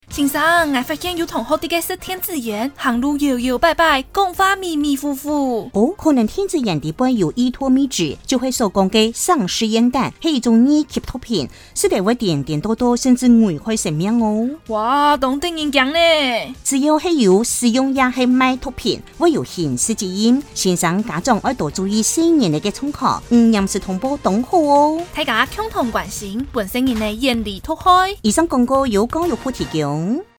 轉知 教育部製作防制依托咪酯廣播廣告